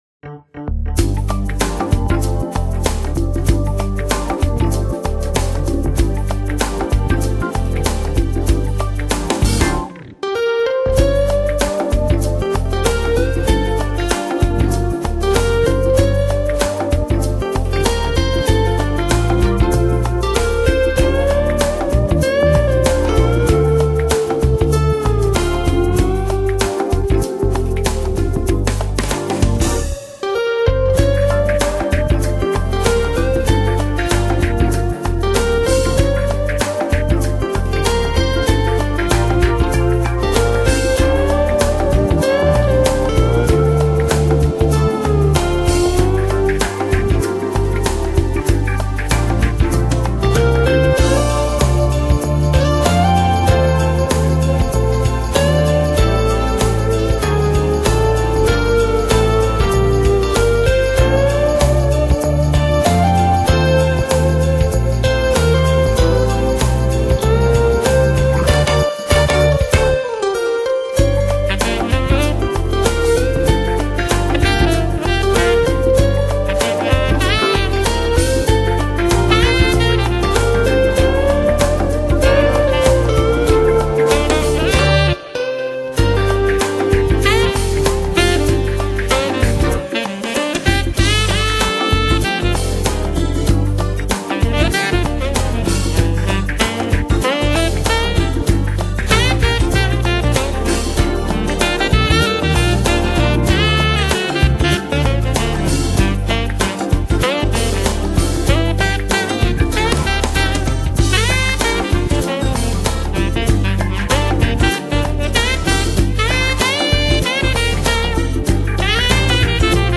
Smooth Jazz Newage 地区